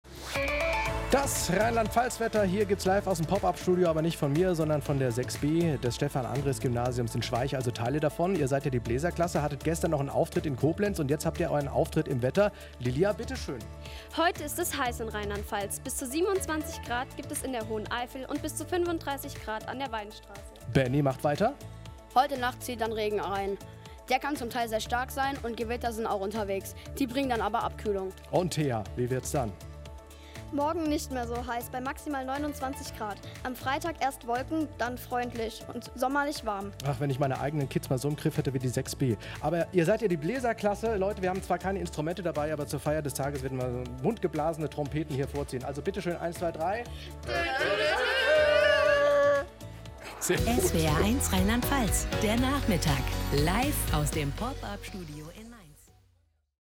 Die Konzerte sorgten für große Begeisterung und wurden mit viel Applaus belohnt.